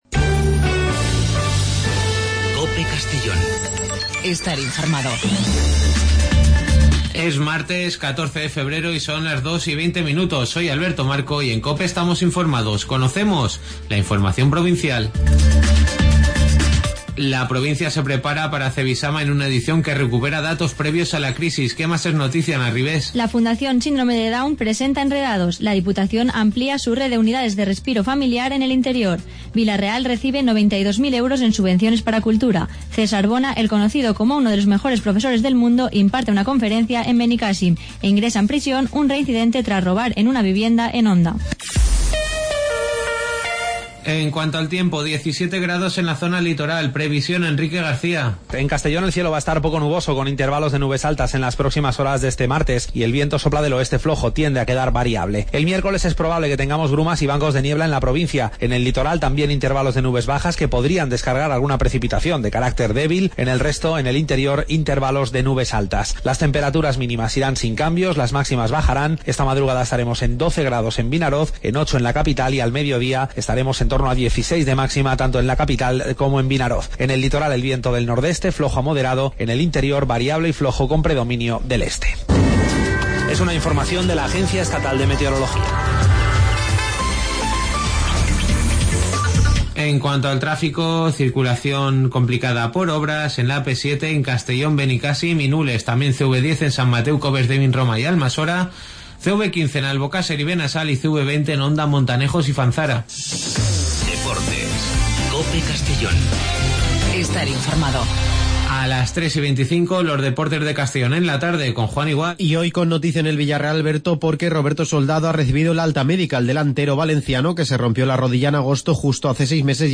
Las noticias del día de 14:20 a 14:30 en Informativo Mediodía COPE en Castellón.